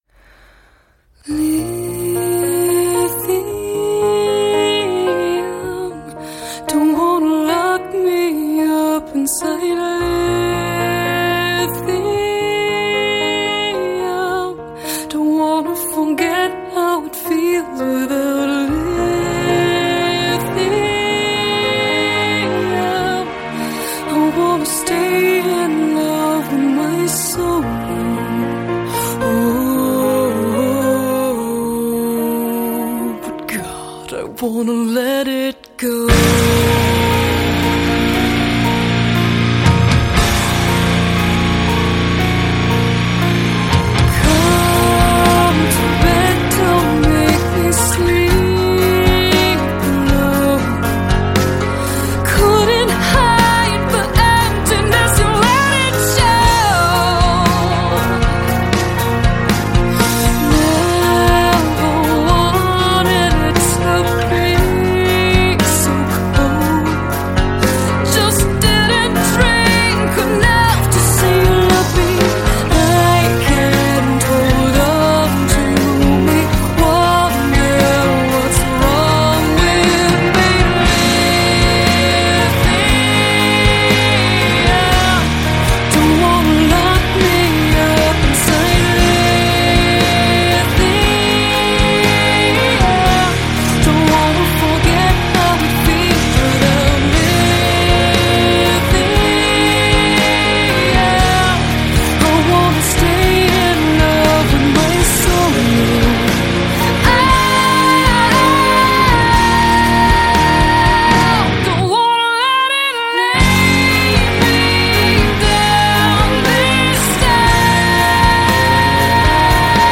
Жанр: Alternative, Gothic Metal